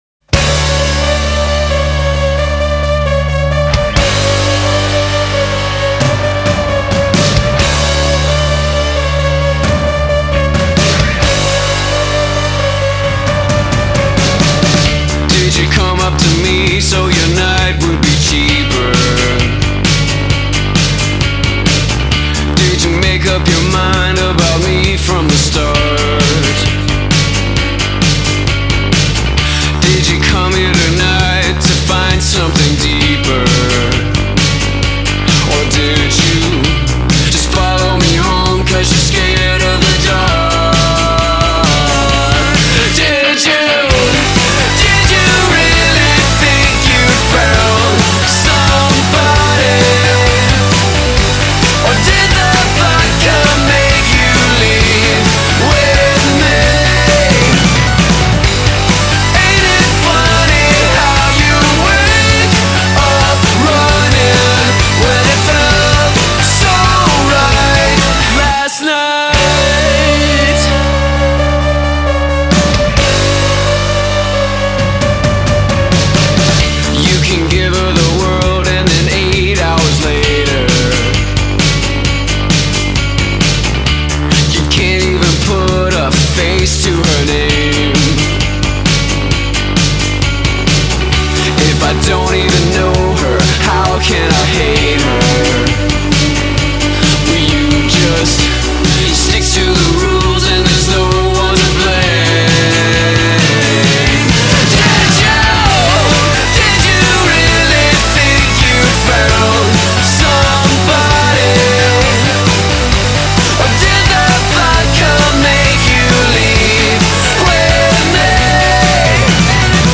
but their pop melodicism set them apart within that format.